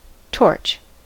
torch: Wikimedia Commons US English Pronunciations
En-us-torch.WAV